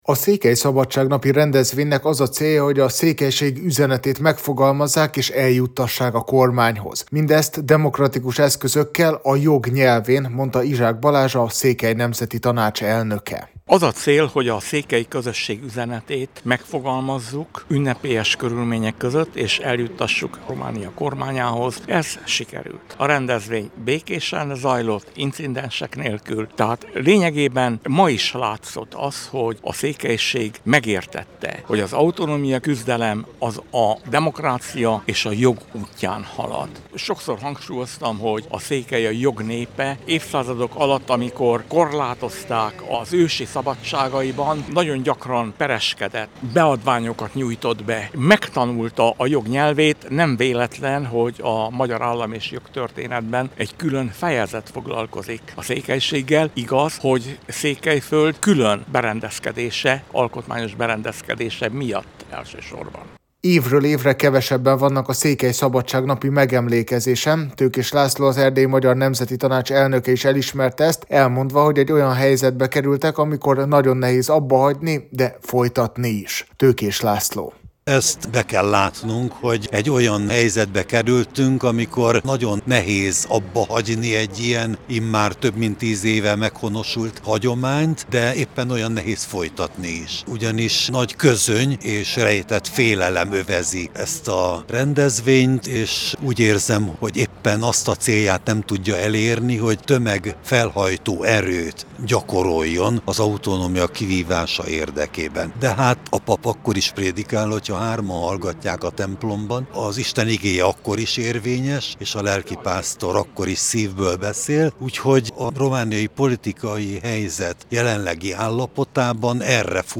Székelyföld területi autonómiáját követelő petíciót fogadott el tegnap Marosvásárhelyen, a székely szabadság napja alkalmából a Székely Vértanúk emlékművénél összegyűlt több száz tüntető.